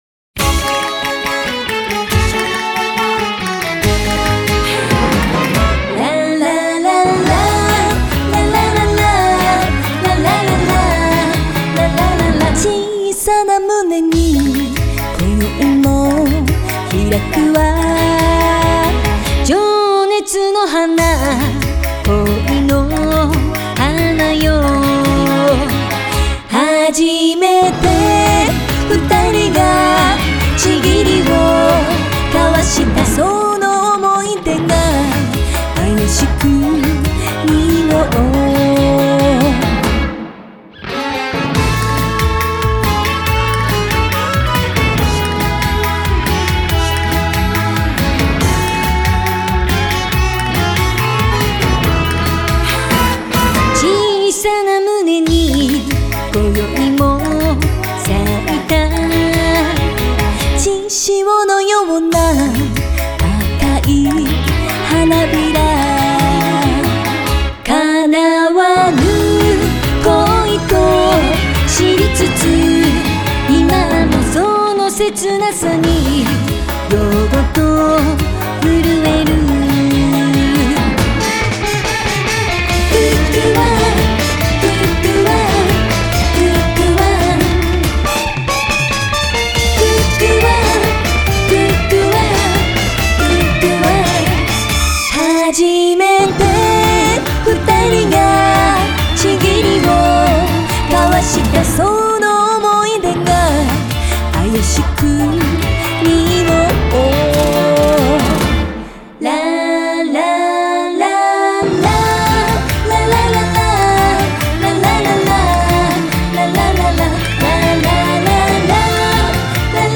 аранжировка направлена на современный лад.